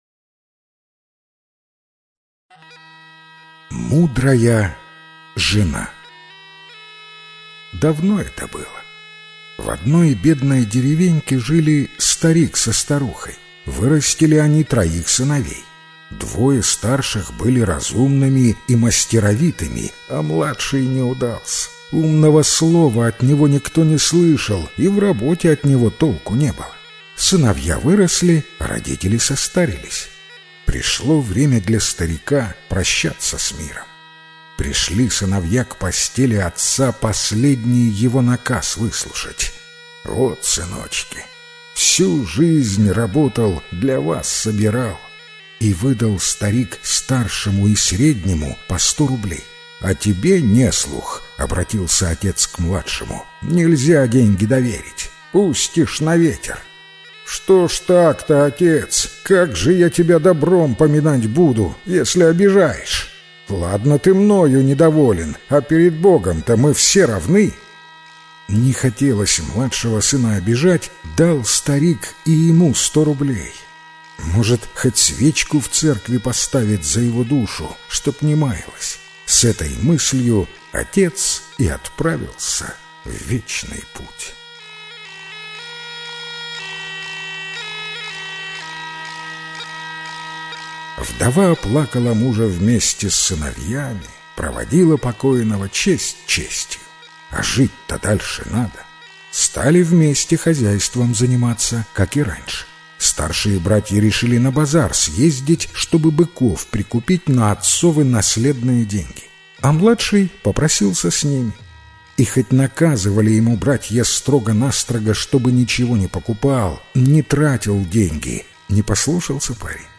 Мудрая жена - белорусская аудиосказка - слушать онлайн